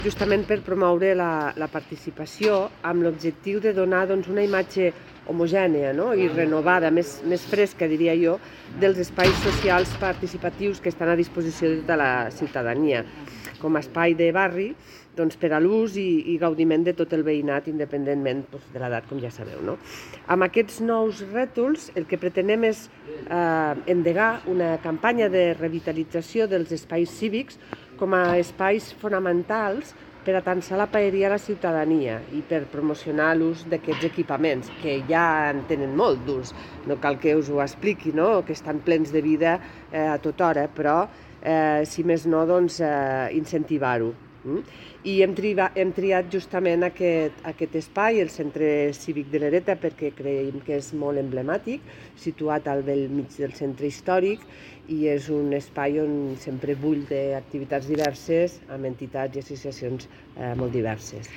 Gispert ha subratllat que amb els nous rètols s’inicia una campanya de revitalització dels espais cívics, com a espais fonamentals per atansar la Paeria a la ciutadania. Tall de veu M.Gispert El primer centre en lluir la nova imatge és el Centre Cívic de l’Ereta, ubicat al mig del Centre Històric i que, com ha apuntat la regidora, és emblemàtic i en fan ús diverses entitats.
tall-de-veu-de-la-regidora-marta-gispert-sobre-la-nova-retolacio-als-centres-civics-i-locals-socials-de-lleida